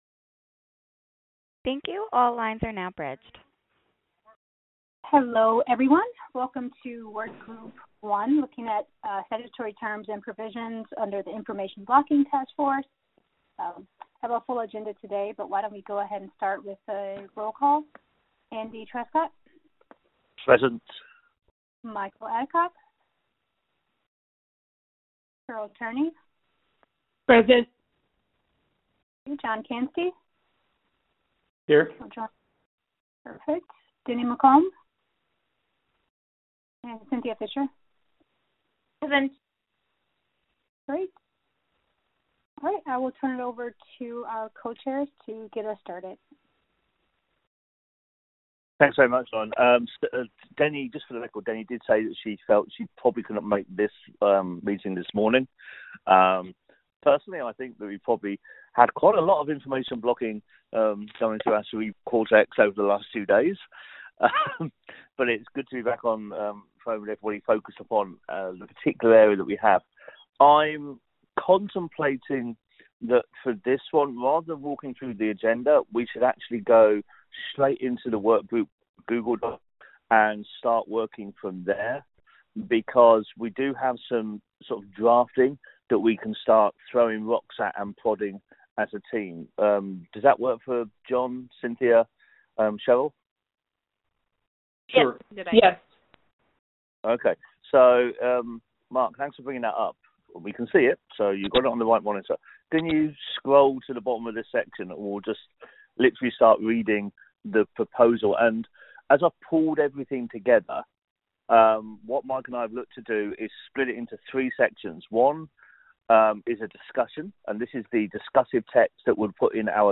2019-03-21_IACC_group1_VirtualMeeting_Audio